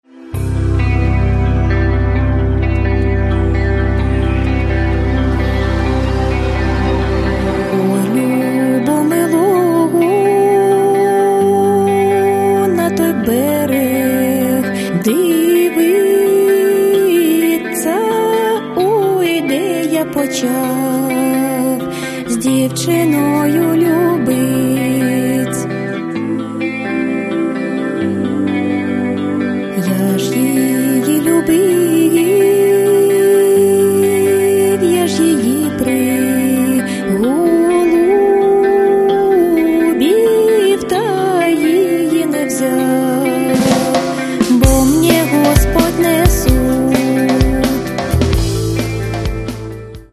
Каталог -> Рок та альтернатива -> Фолк рок